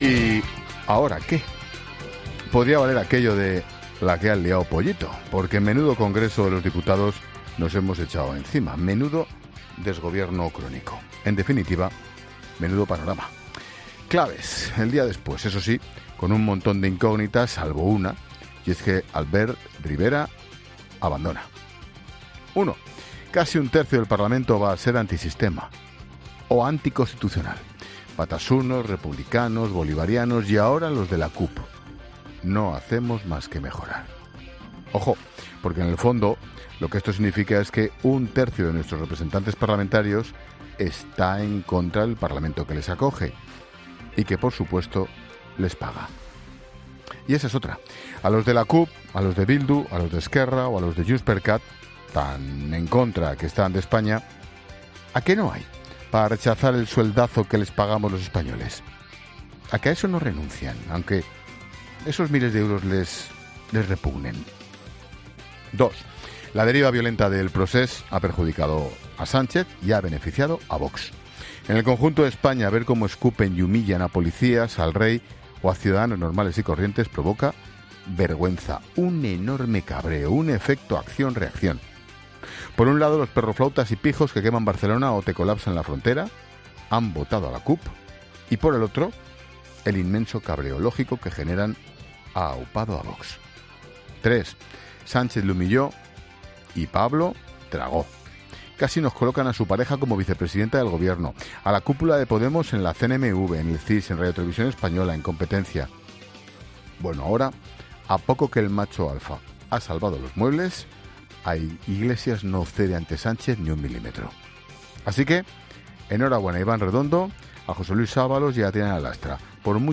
AUDIO: El presentador de La Linterna analiza los resultados de las elecciones generales y la dimisión de Albert Rivera
Monólogo de Expósito